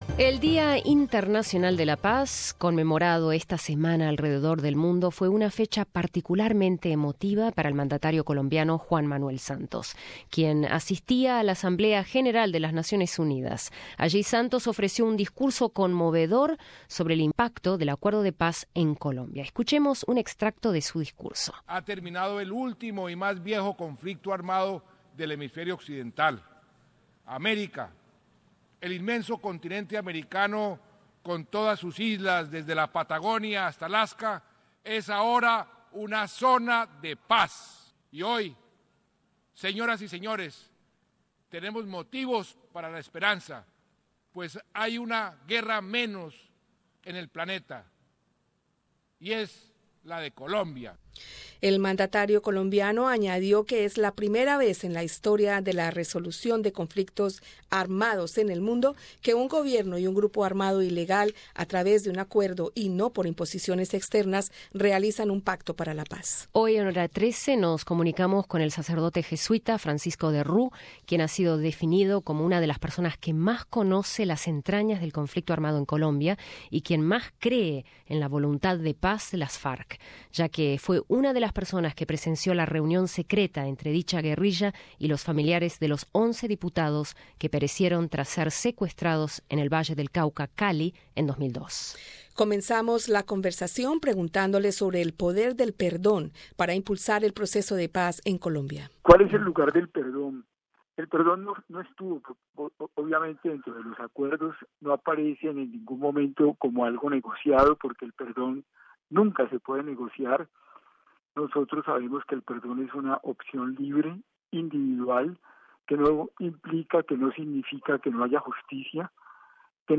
En esta edición sobre el proceso de la paz en Colombia te acercamos una conversación con alguien que ha vivido de cerca el conflicto entre el gobierno y las FARC, el Padre Francisco de Roux.